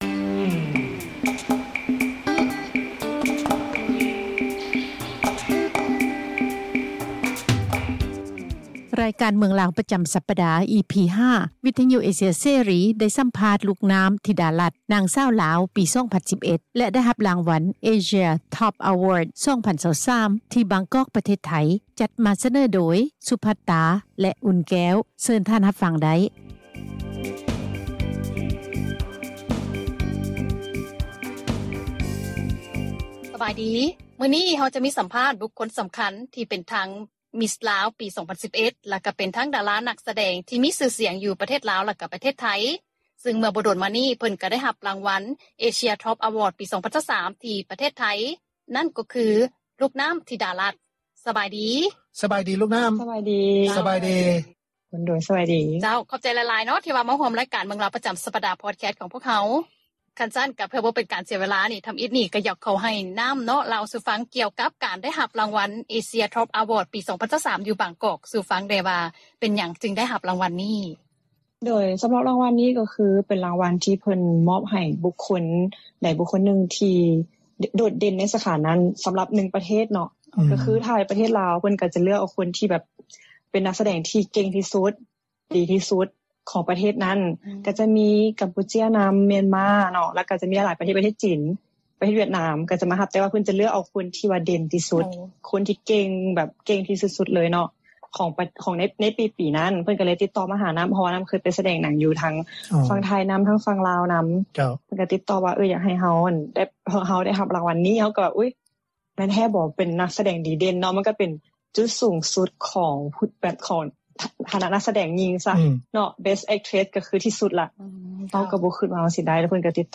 ສໍາພາດ ລູກນໍ້າ ທິດາລັດ